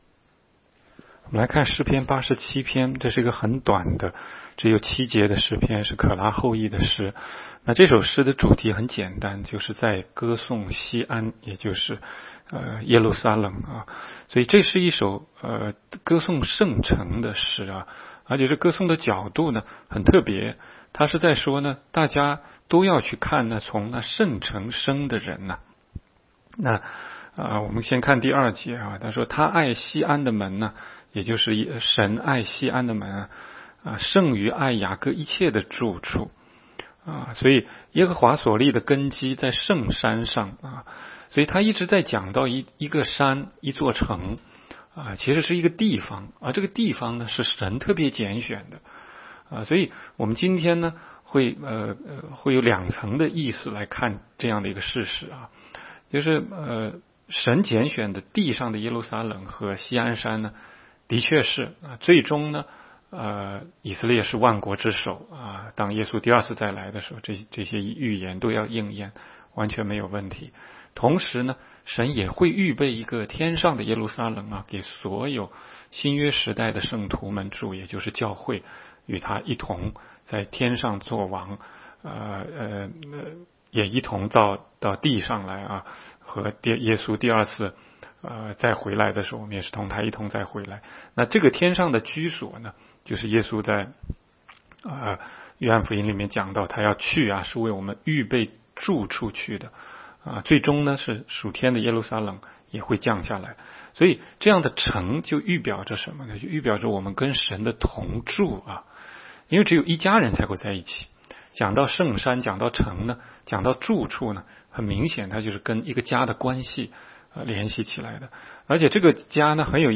16街讲道录音 - 每日读经-《诗篇》87章